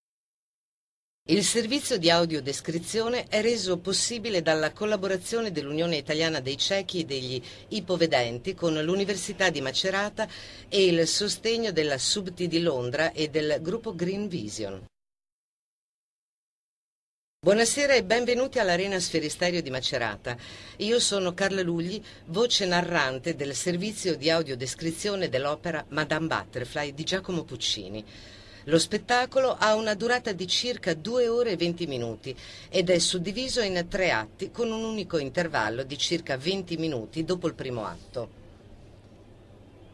InclusivOpera: le audiodescrizioni